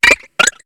Cri de Vivaldaim dans Pokémon HOME.